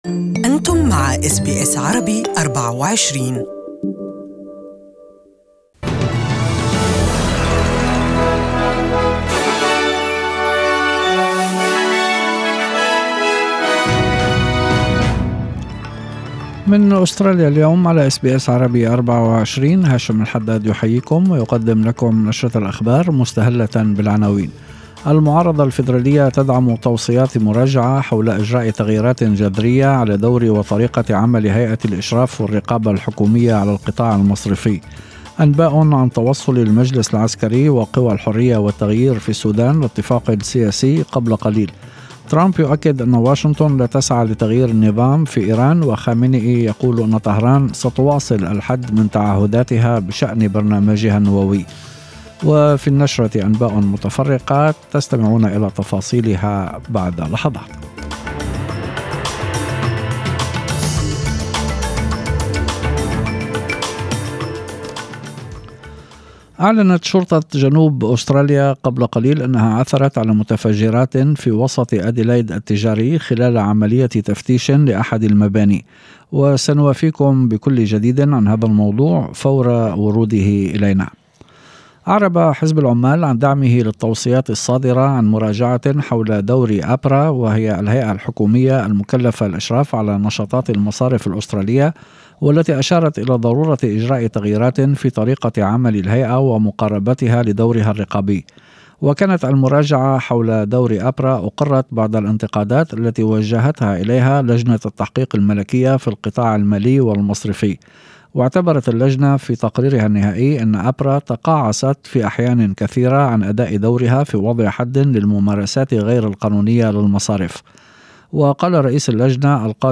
This is 5pm news from SBS Arabic24